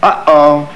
UHOH.WAV